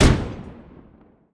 TANK_Shoot_07_mono.wav